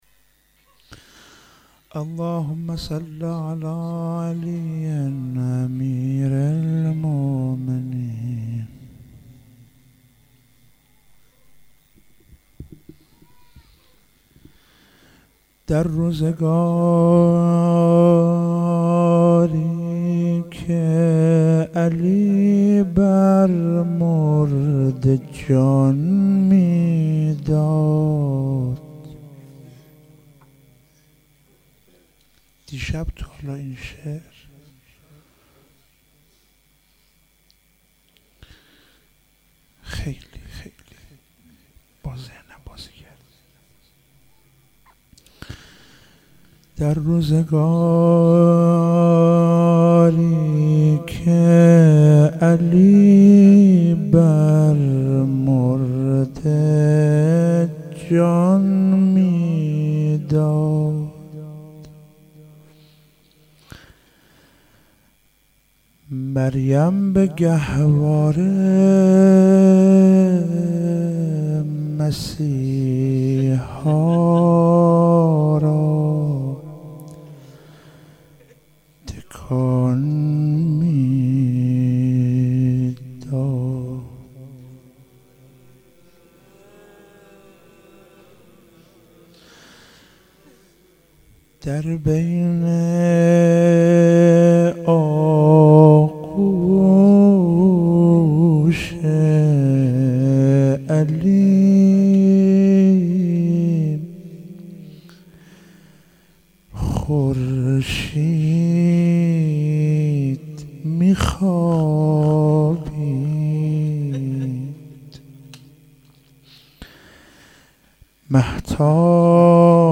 فاطمیه 95 - روضه